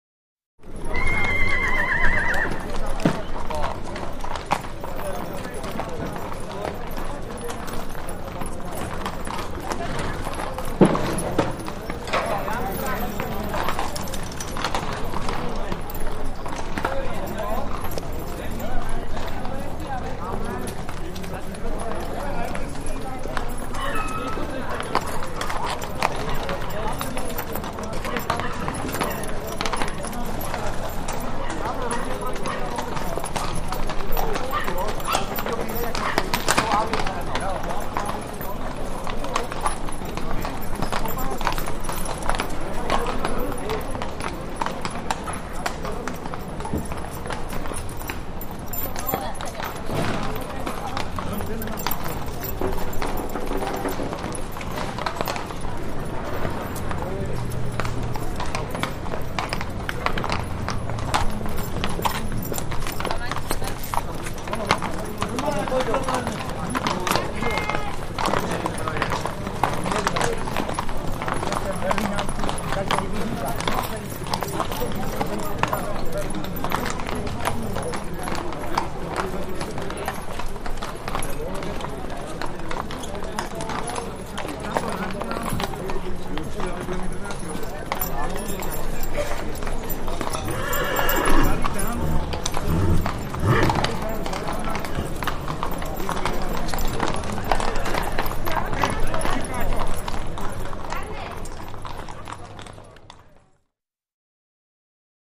Street BG
City Street 01, Busy; City Street With Walla, Horses Hooves On Cobbled Stone, Whinnies, Shouts Etc.